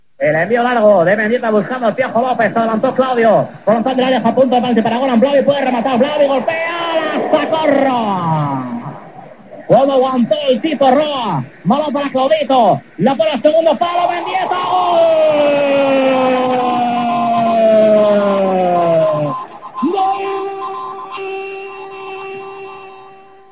Narració d'un gol del València en el seu partit contra el Mallorca.
Esportiu